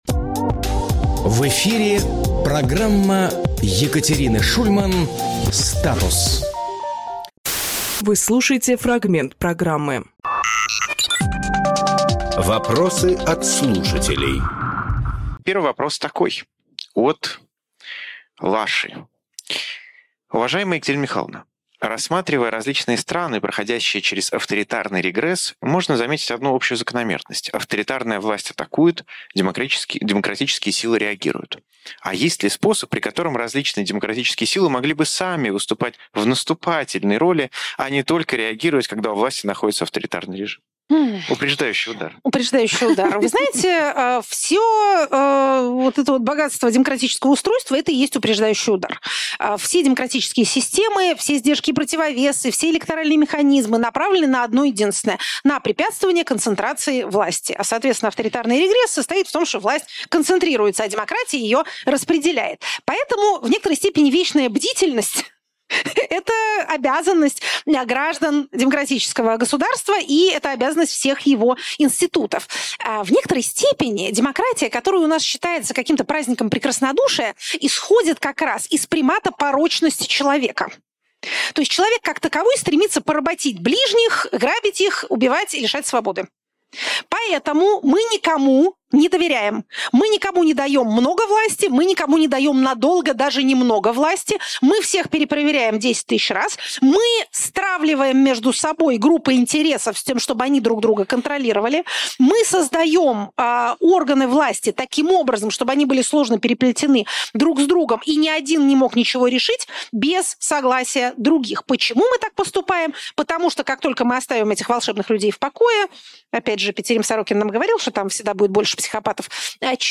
Екатерина Шульманполитолог
Фрагмент эфира от 10.02.26